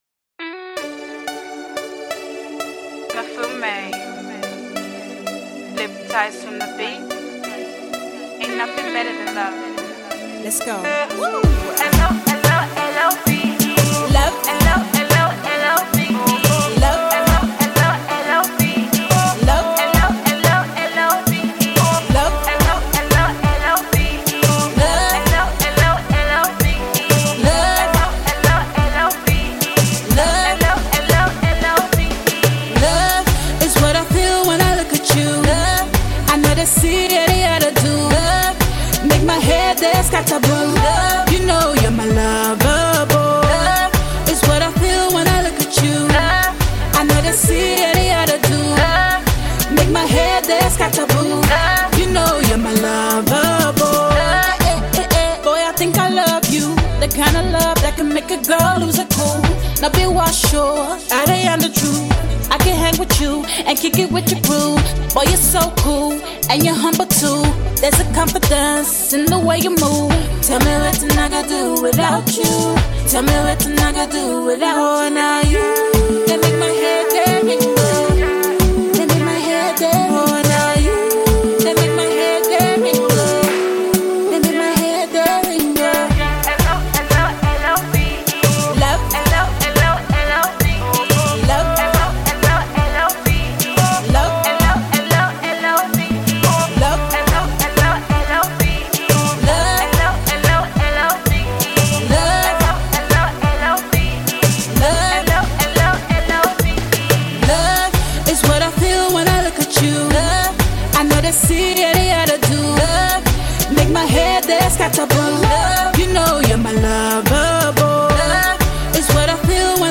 funky party tunes